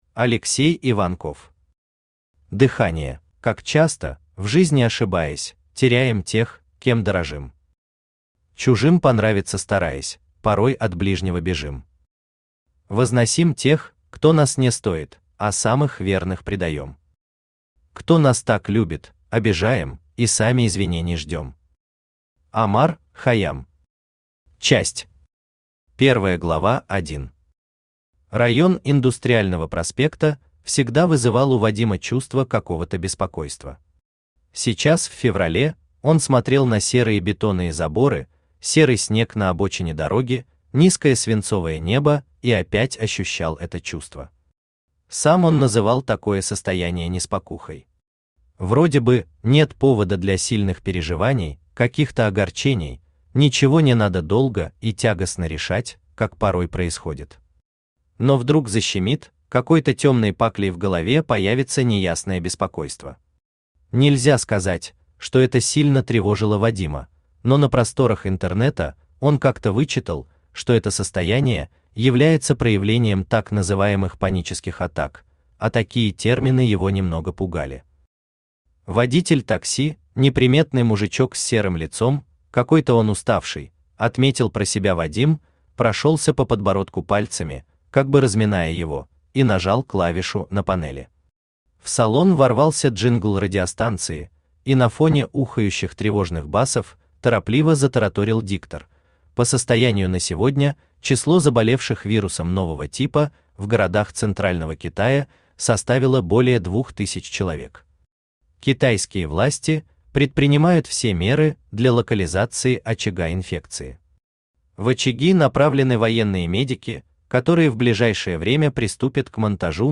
Аудиокнига Дыхание | Библиотека аудиокниг
Aудиокнига Дыхание Автор Алексей Иванков Читает аудиокнигу Авточтец ЛитРес.